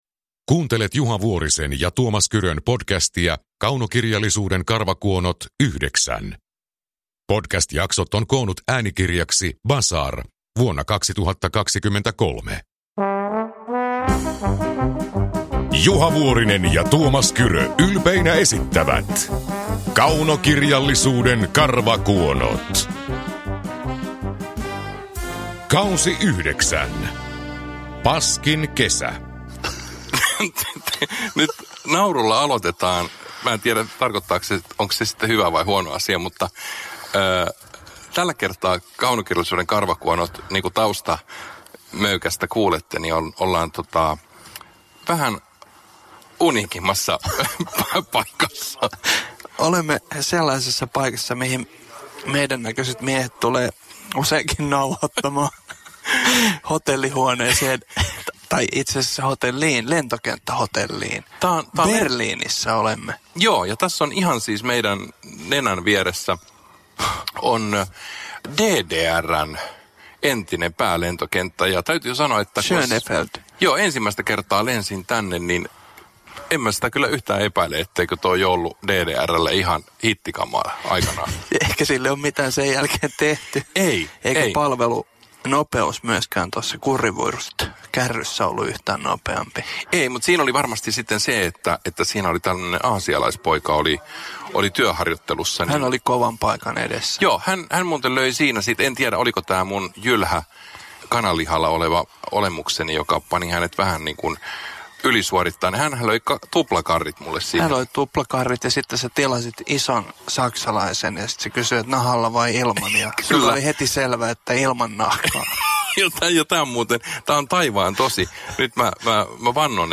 Uppläsare: Tuomas Kyrö, Juha Vuorinen